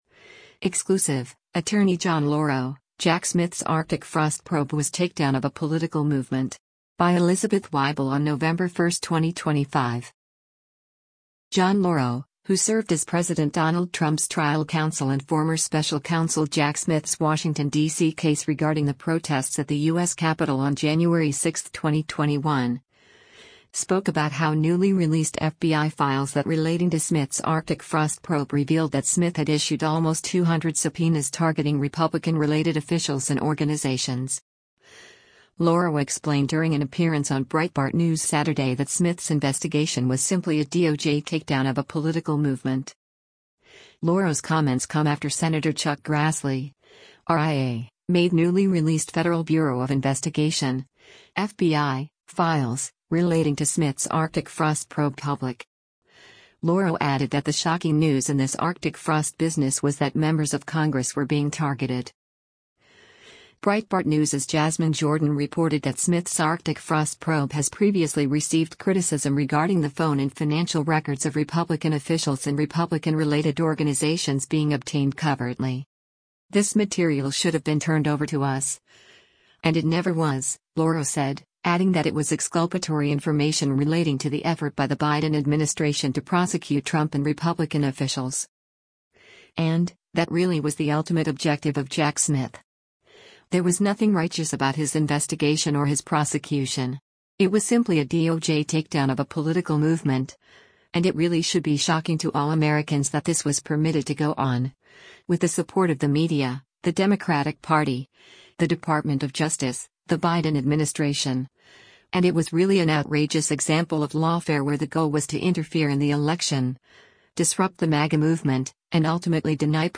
Lauro explained during an appearance on Breitbart News Saturday that Smith’s investigation was “simply a DOJ takedown of a political movement.”